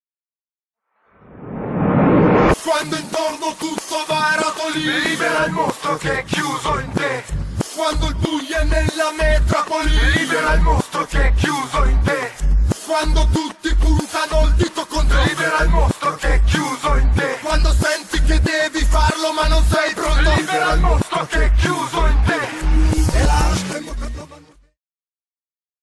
rap
Tipo di backmasking Rovesciato